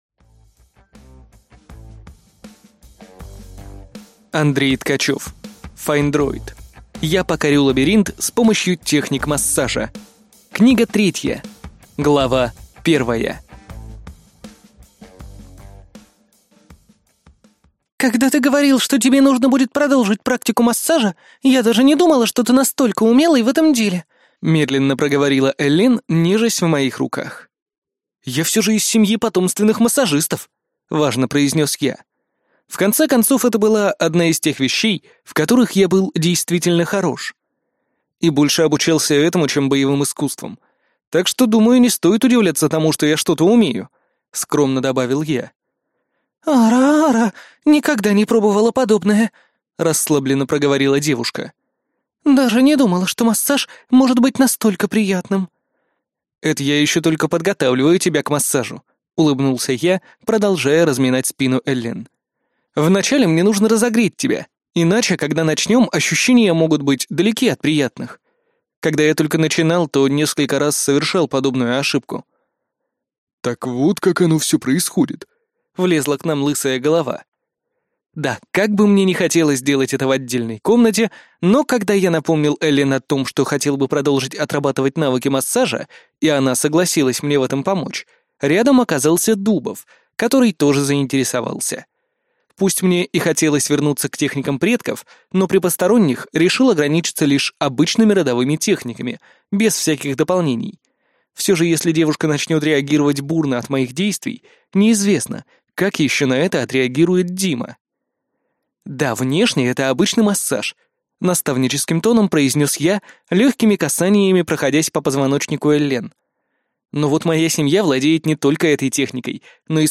Аудиокнига Я покорю Лабиринт с помощью техник массажа. Книга 3 | Библиотека аудиокниг